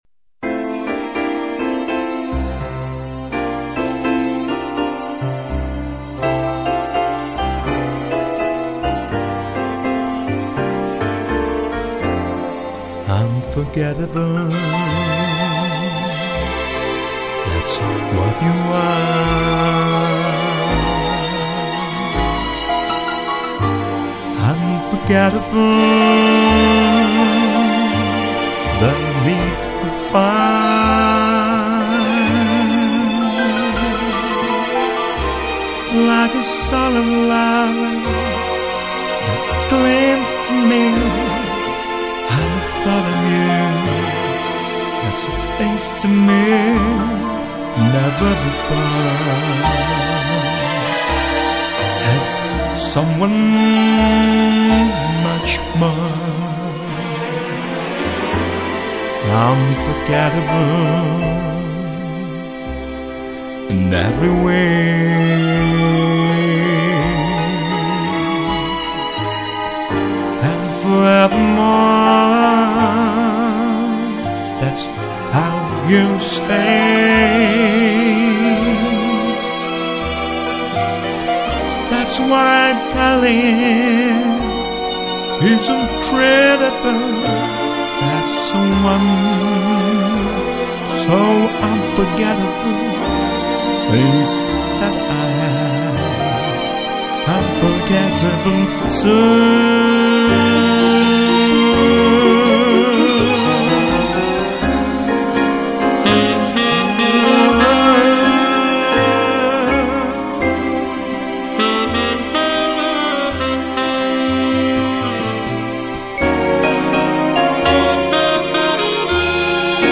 А сколько шарма в тембре!